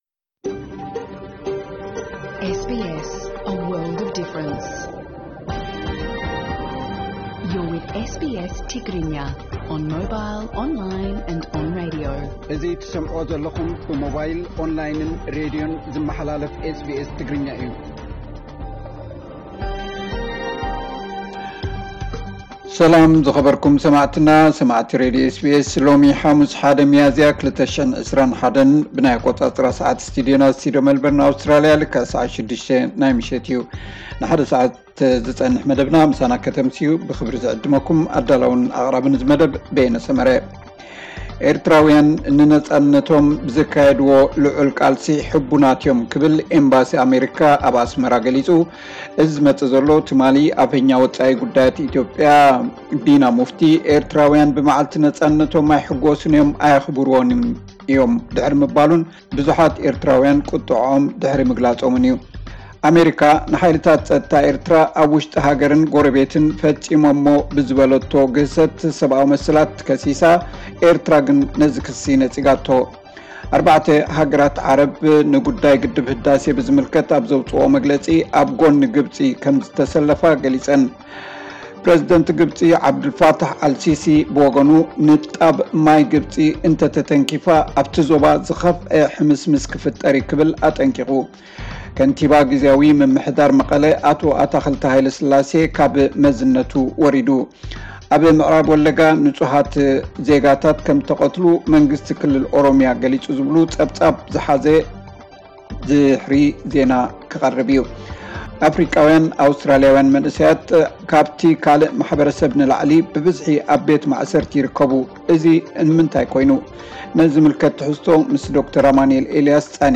ዕለታዊ ዜና 1 ሚያዚያ 2021 SBS ትግርኛ